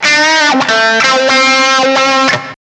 134 GTR 5 -L.wav